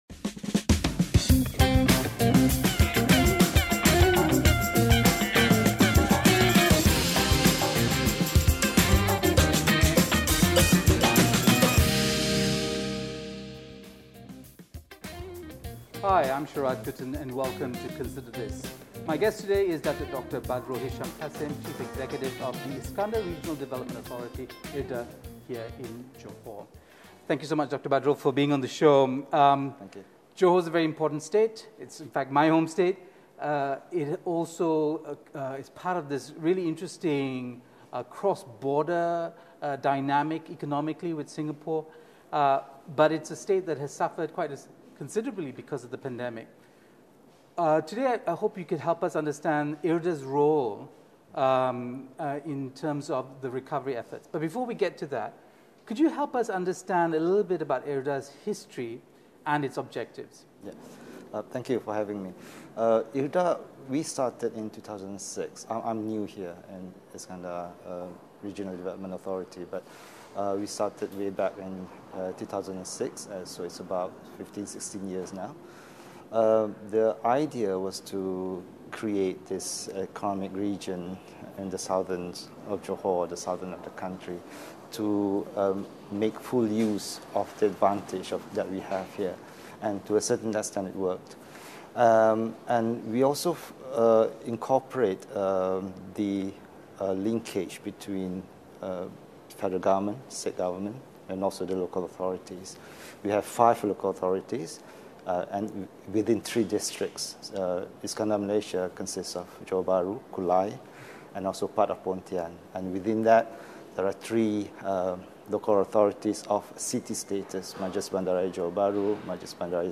speaks to our guest about past vulnerabilities & future possibilities.